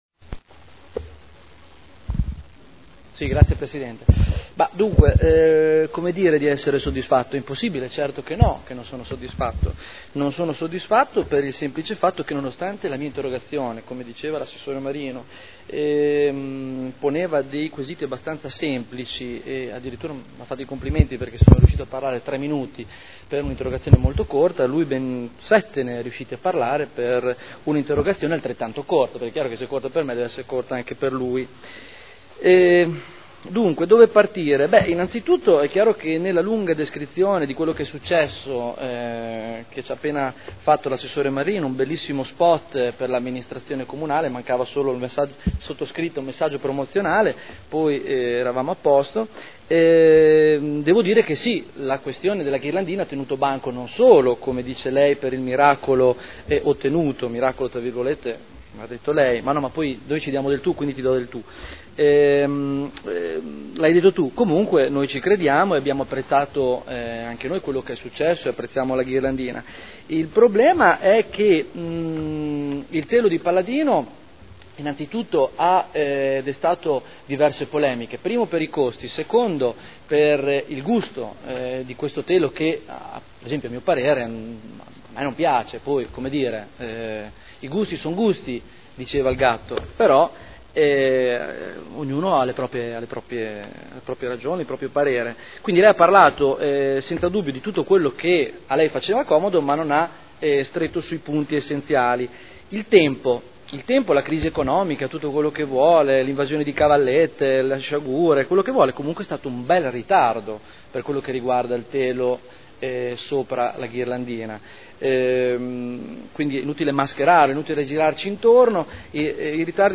Seduta del 23 gennaio Interrogazione del consigliere Barberini (Lega Nord) avente per oggetto: “Lavori alla Ghirlandina” Replica